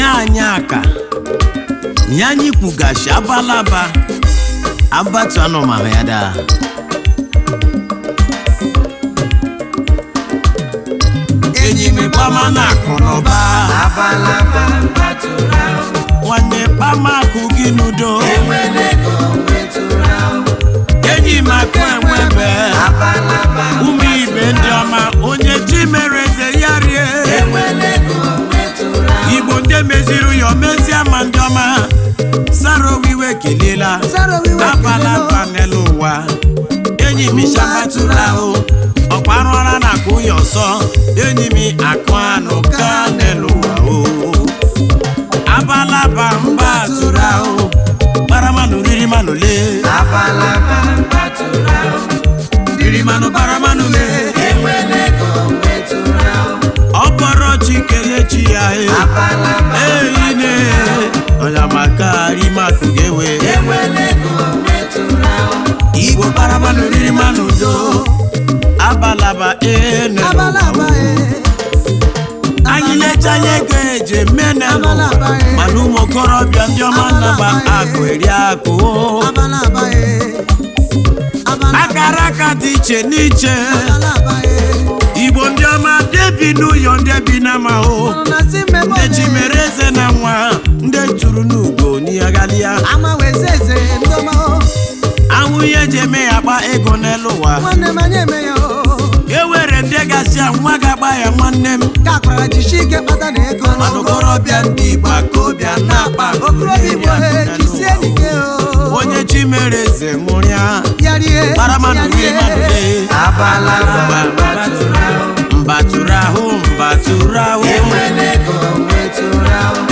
highlife track
a good highlife tune